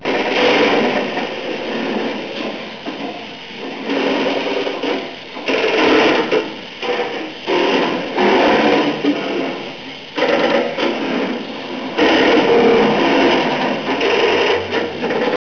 Sound of a 10,000 line Strowger Public exchange 167k (15 secs)